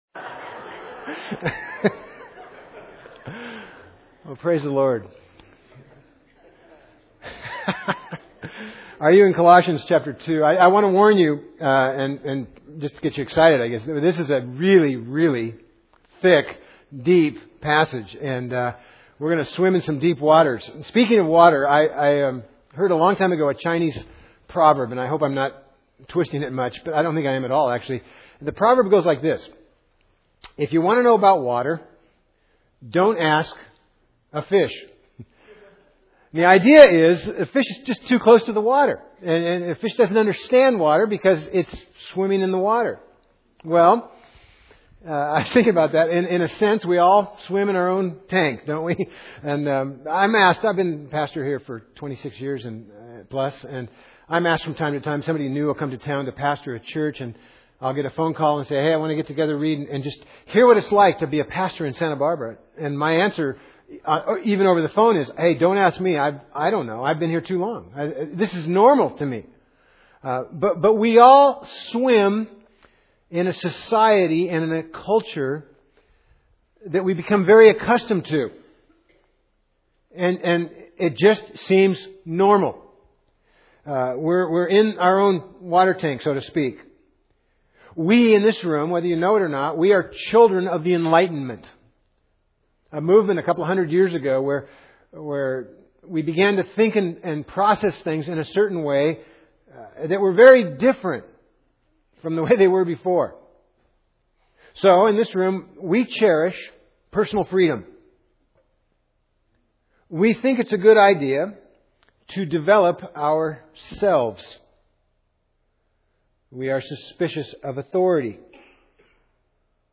Colossians Service Type: Sunday Preacher